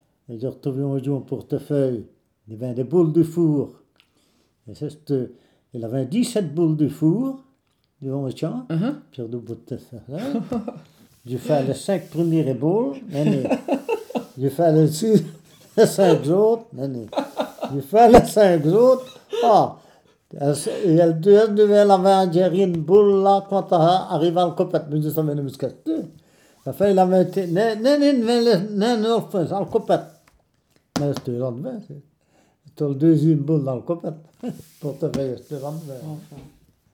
s'exprime dans le wallon de son village,
Aisomont (Ardenne belge)